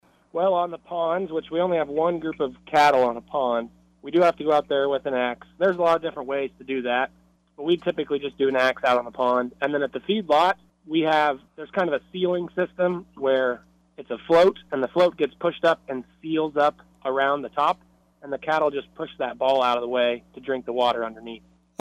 joined in on the KSAL Morning News Extra